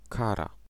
Ääntäminen
US : IPA : ['pʌn.ɪʃ.mənt]